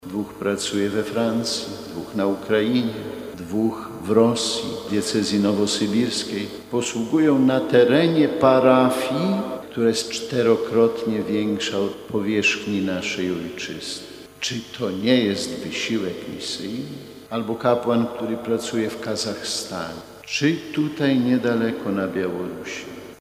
Centralnym punktem obchodów Nadzwyczajnego Miesiąca Misyjnego w diecezji warszawsko-praskiej była 24 października uroczysta msza św. w bazylice katedralnej św. Michała Archanioła i św. Floriana Męczennika.